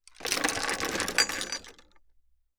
Metal_07.wav